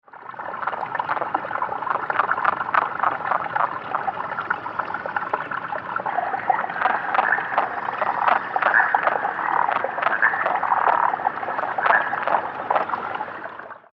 Sounds of Sierra Nevada Yellow-legged Frog - Rana sierrae
It is a short and rasping call often accellerated and rising at the end, sometimes preceeded by calls that don't rise at the end.
These recordings were made in the air at an inlet to a pond on a late Spring afternoon on a different year than the recordings heard above, but in the same location in Alpine County shown below.
They are also very quiet in volume, and there is incessant loud running water near them, so even with a powerful microphone aimed directly at them, it is still difficult to hear them on a recording.
Sound  This is a 49 second recording of the calls of several different frogs, which have been edited together.